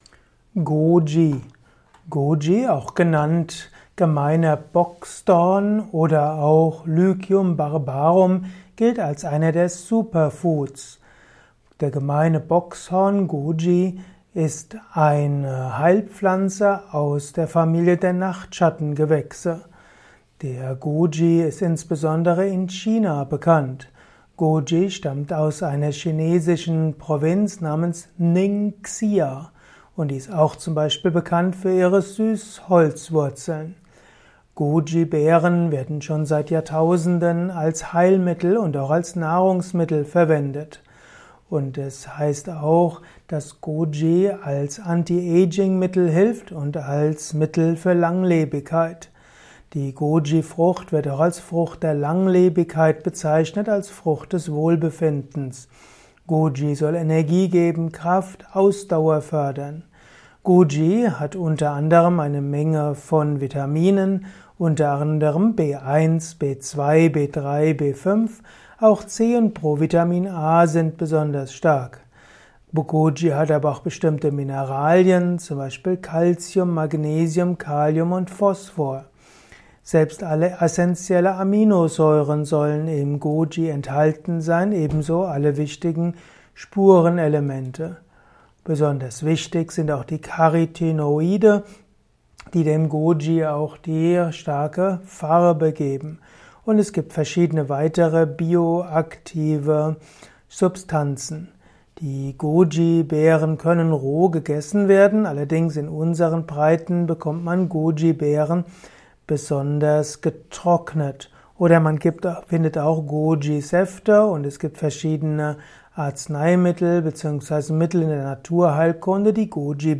Ein Kurzvortrag über den Begriff Goji